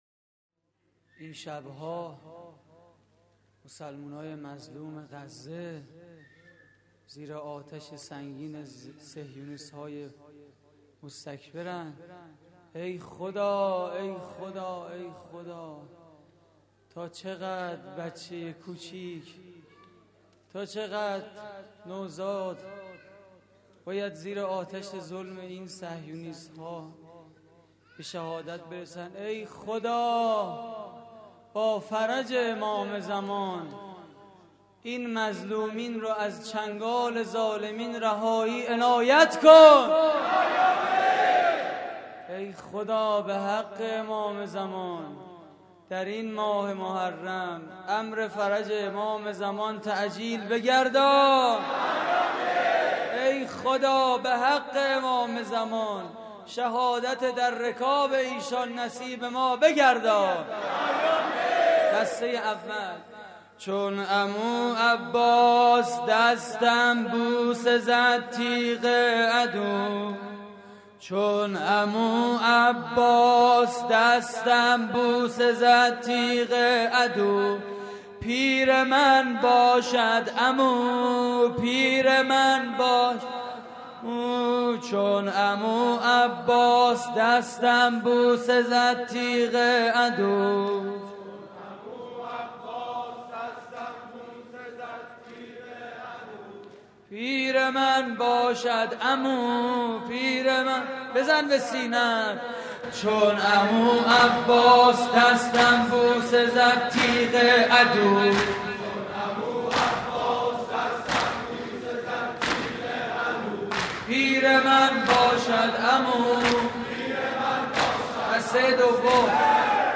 مراسم عزاداری شب پنجم ماه محرم